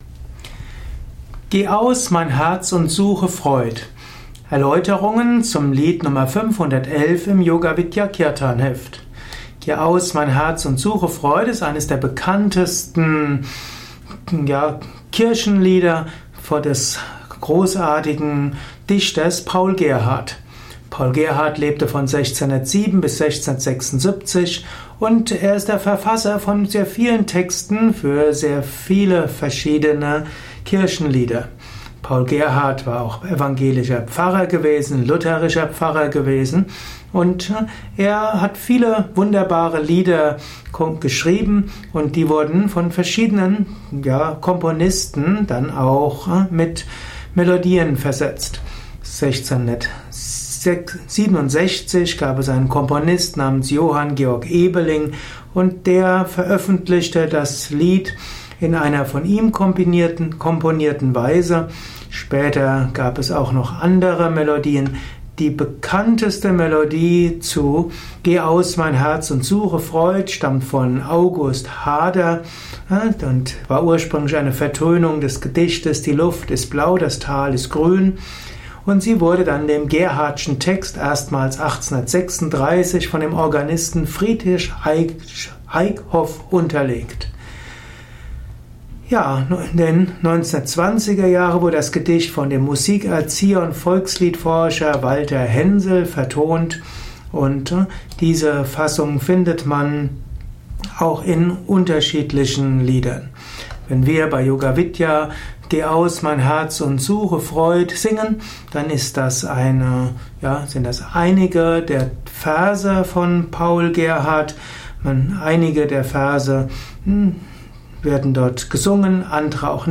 Yoga Vidya Kirtanheft , Tonspur eines Kirtan Lehrvideos.